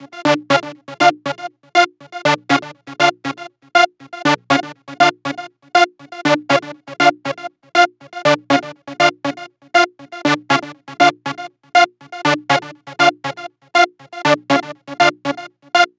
TSNRG2 Lead 009.wav